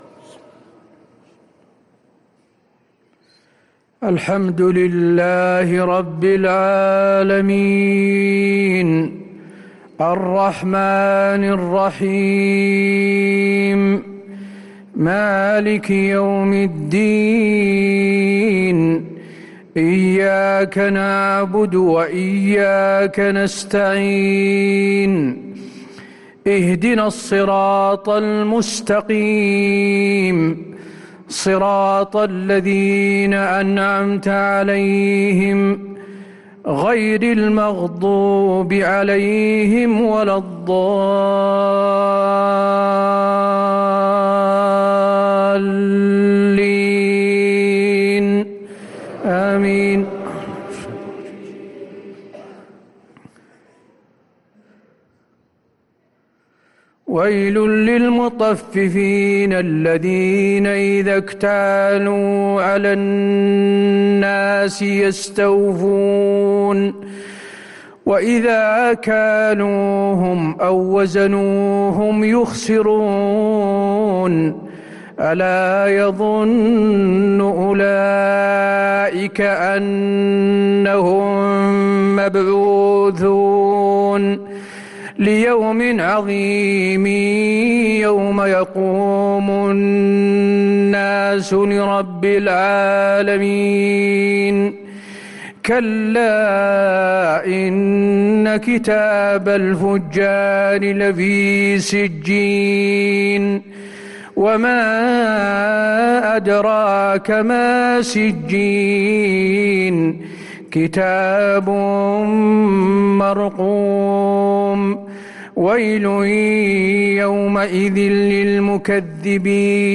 عشاء الأحد 8-2-1444هـ من سورة المطففين 1-28 | Isha prayer from Surat Al-Mutaffifin 4-9-2022 > 1444 🕌 > الفروض - تلاوات الحرمين